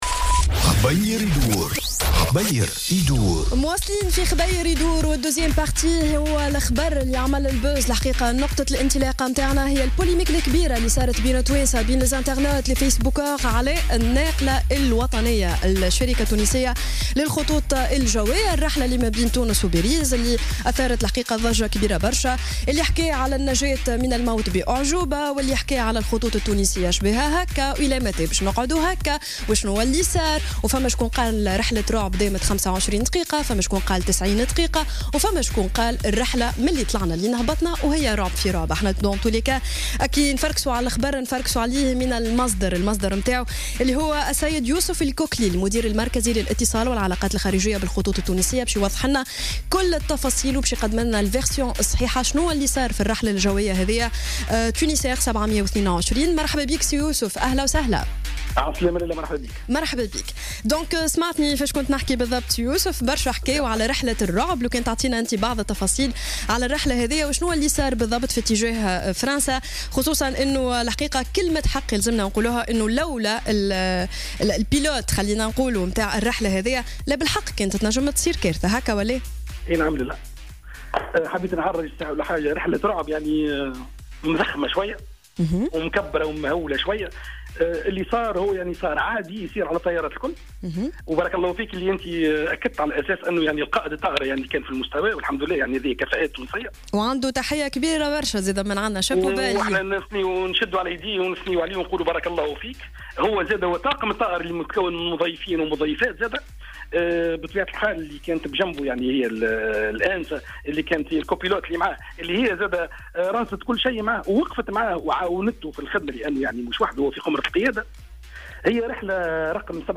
مداخلة هاتفية في برنامج "happy-days" على موجات الجوهرة اف ام